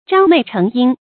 张袂成阴 zhāng mèi chéng yīn 成语解释 张开袖子能遮掩天日，成为阴天。形容人多。
ㄓㄤ ㄇㄟˋ ㄔㄥˊ ㄧㄣ